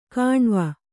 ♪ kāṇva